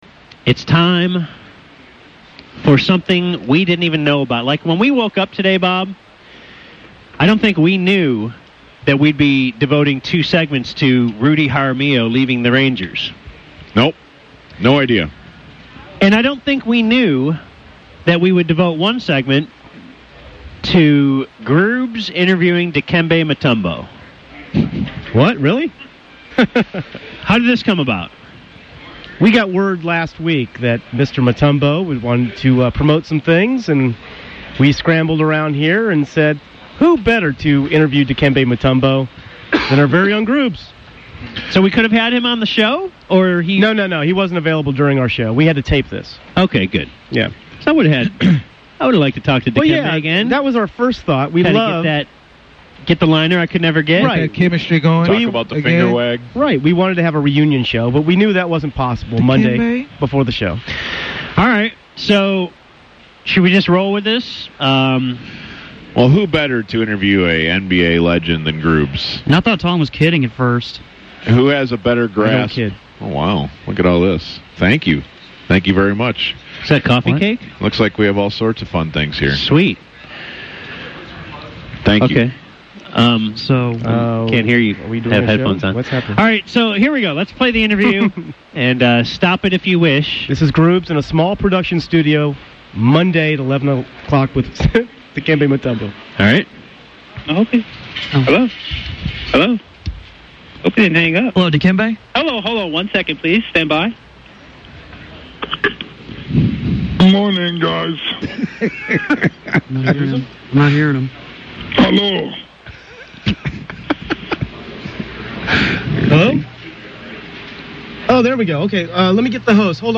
Probably the most uncomfortable interview I have ever heard on radio.